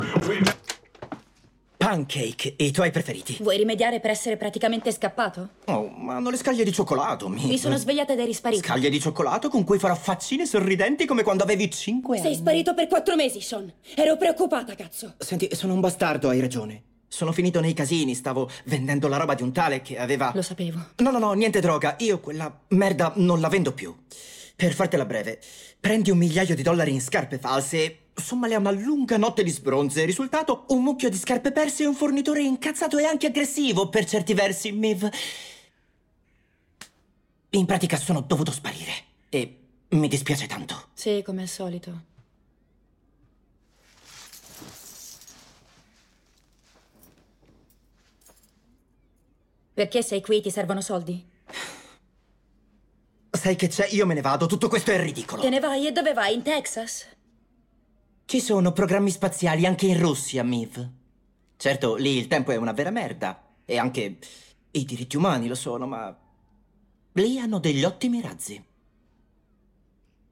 nel telefilm "Sex Education", in cui doppia Edward Bluemel.